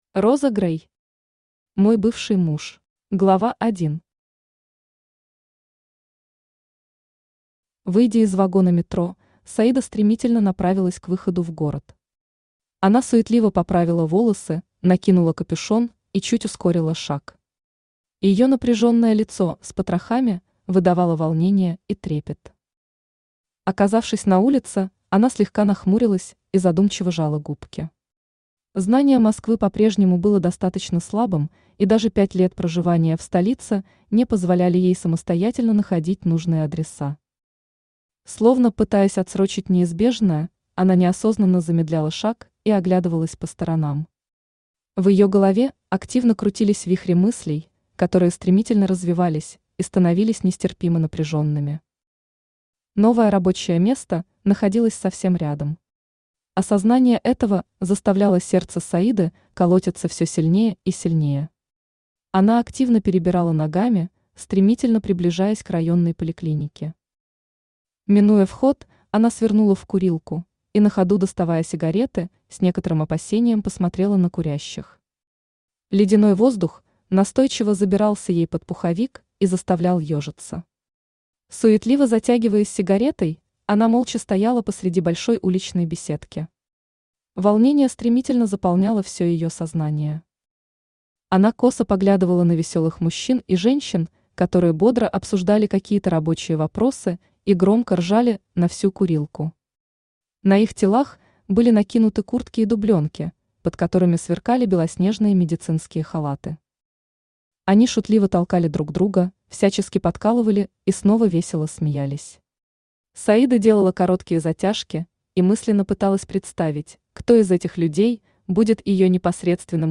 Аудиокнига Мой бывший муж | Библиотека аудиокниг
Aудиокнига Мой бывший муж Автор Роза Грей Читает аудиокнигу Авточтец ЛитРес.